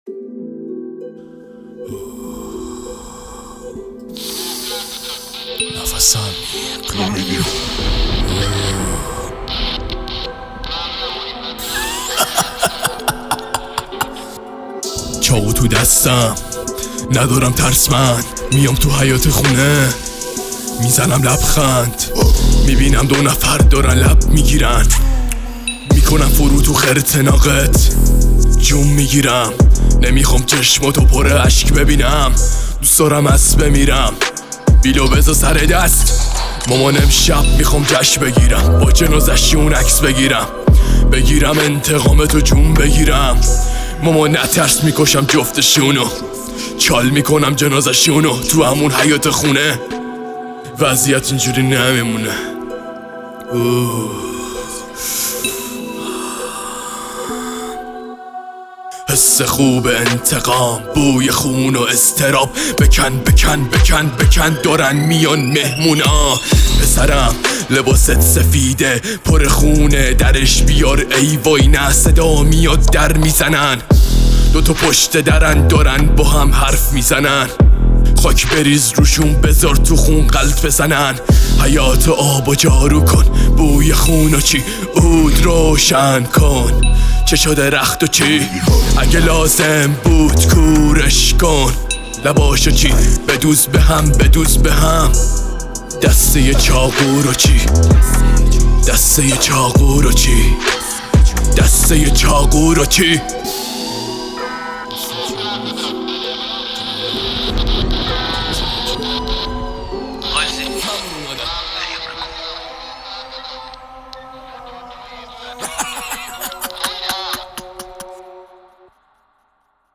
رپفارسی